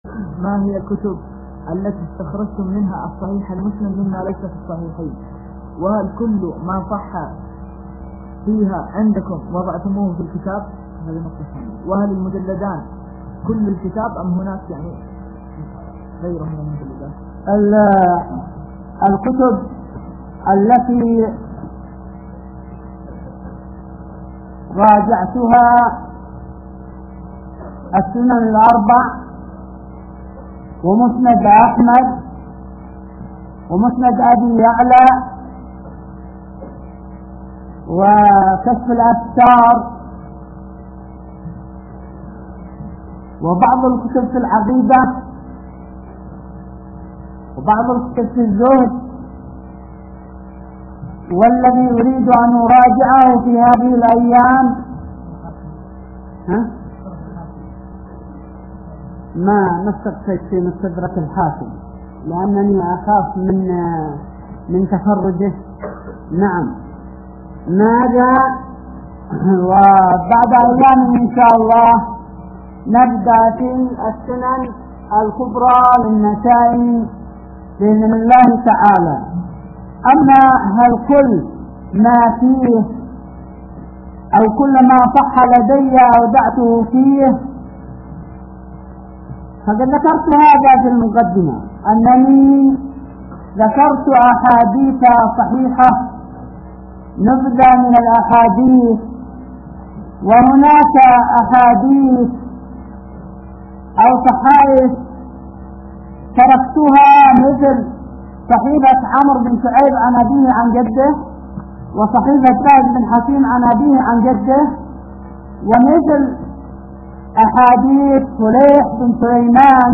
ماهي الكتب التي استخرجتم منها كتاب الصحيح المسند مماليس في الصحيحين | فتاوى الشيخ مقبل بن هادي الوادعي رحمه الله